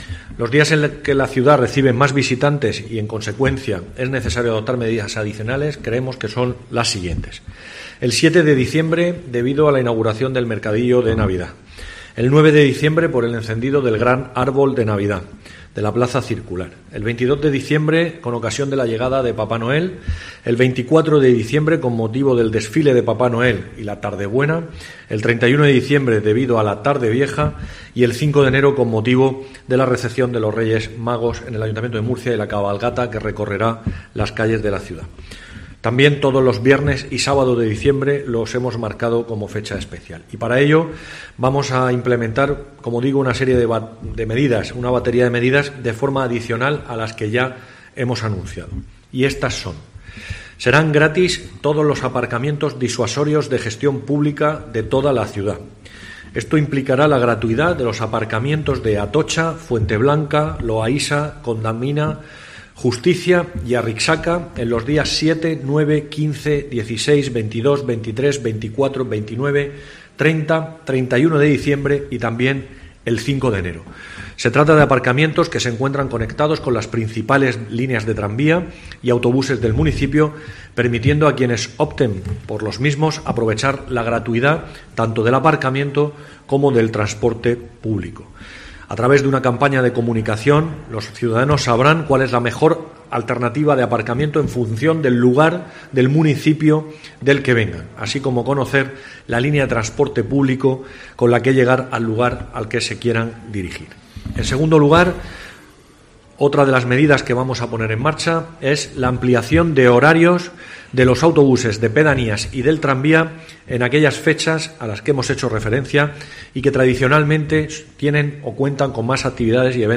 José Francisco Muñoz, concejal de Movilidad